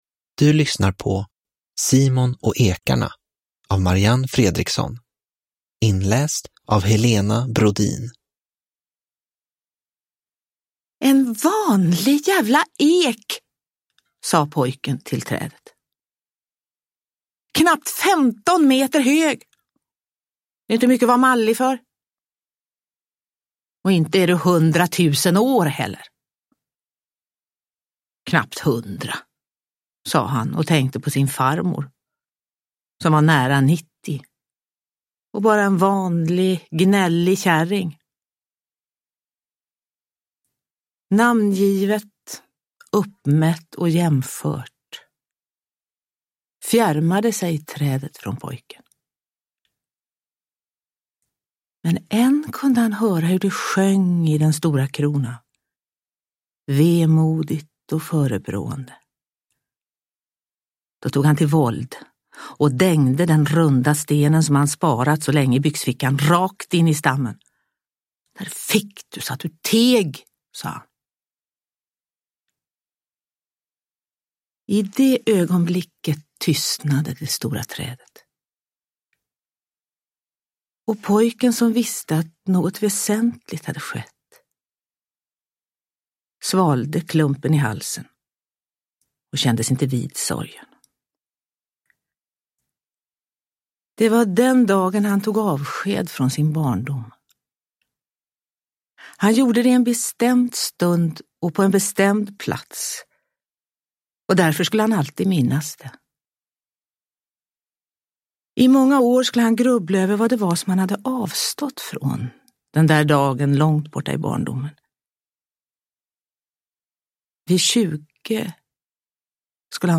Simon och ekarna – Ljudbok – Laddas ner
Ny remastrad version!
Uppläsare: Helena Brodin